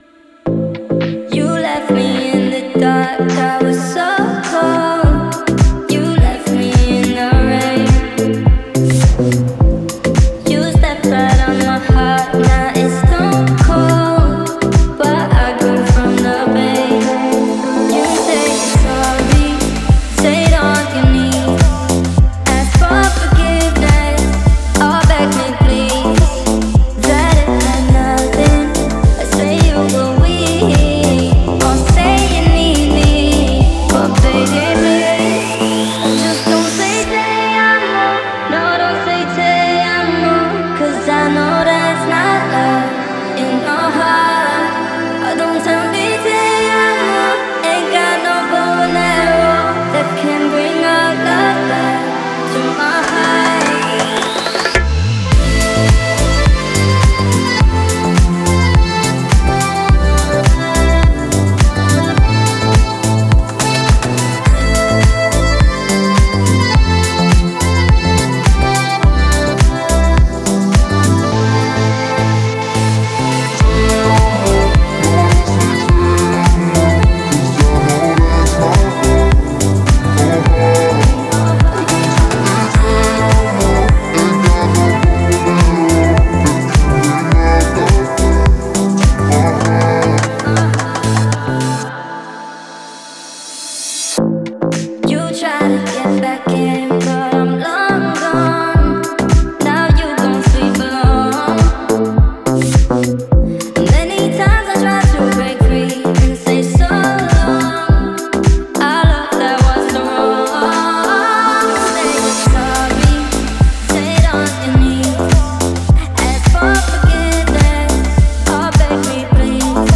это романтическая композиция в жанре латинской поп-музыки